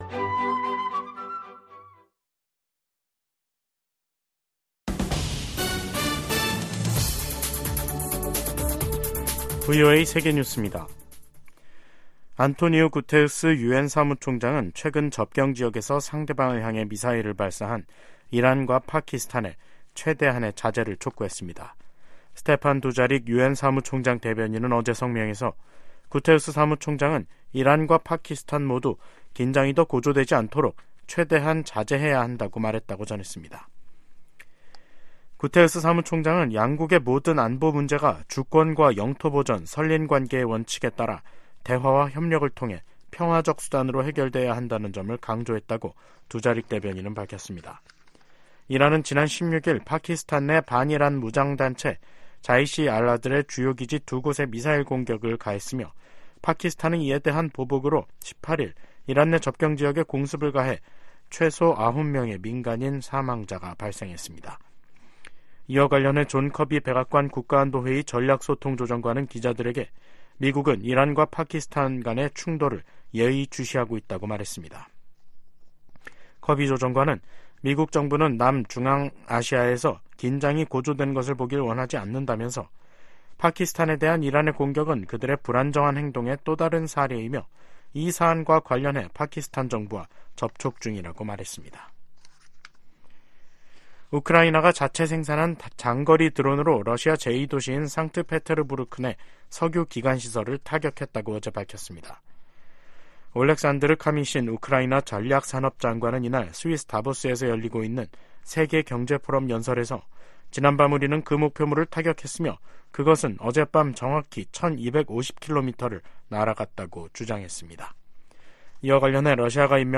VOA 한국어 간판 뉴스 프로그램 '뉴스 투데이', 2024년 1월 19일 2부 방송입니다. 북한이 수중 핵무기 체계 '해일-5-23'의 중요 시험을 동해 수역에서 진행했다고 발표했습니다. 유엔 안전보장이사회가 새해 들어 처음 북한 관련 비공개 회의를 개최한 가운데 미국은 적극적인 대응을 촉구했습니다. 최근 심화되는 북러 군사협력으로 향후 10년간 북한의 역내 위협 성격이 급격하게 바뀔 수 있다고 백악관 고위 당국자가 전망했습니다.